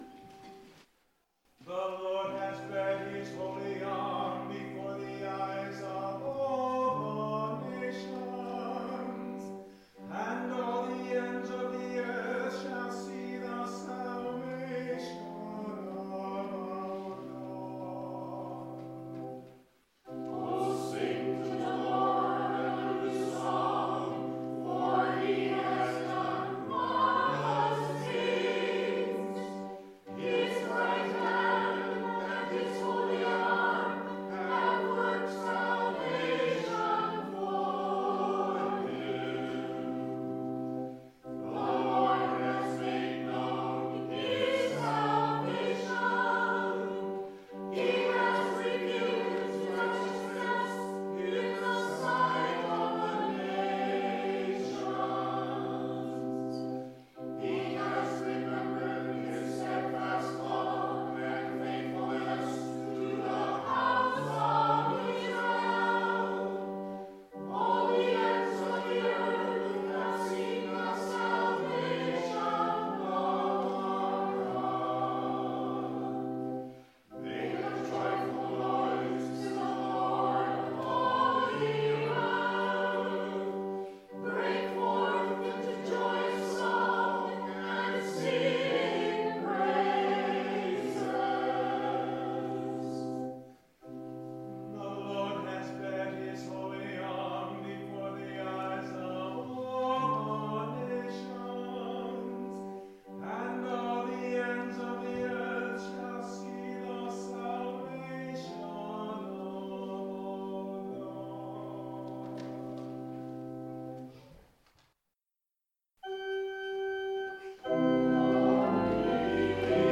The sermon I’ve labeled a New Year meditation because at New Year we often think or plan or just dream about what kind of growth we might attempt over the next year.